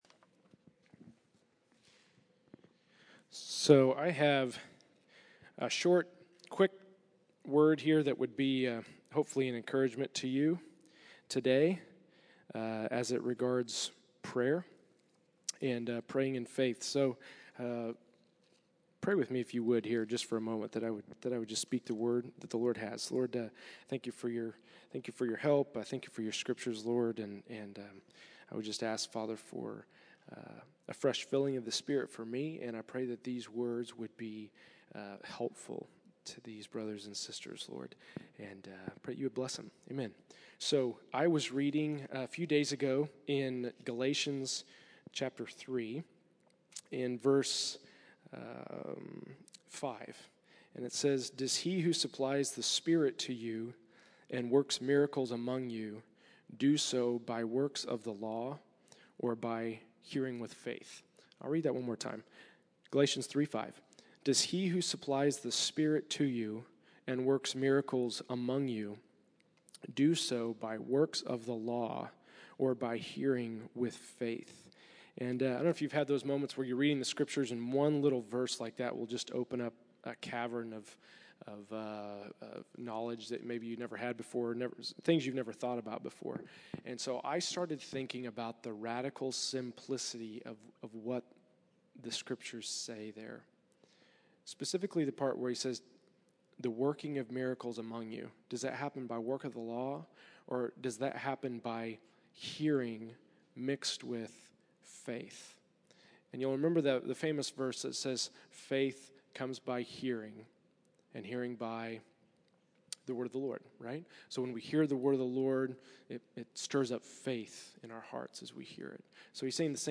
Category: Encouragements